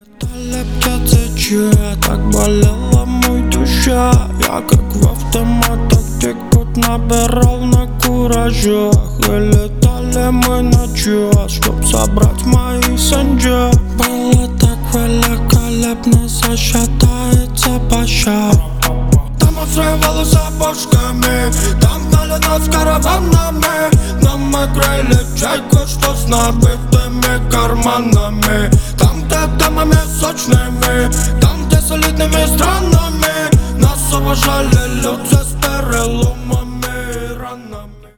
Рэп и Хип Хоп
клубные